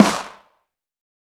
TC2 Snare 6.wav